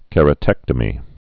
(kĕrə-tĕktə-mē)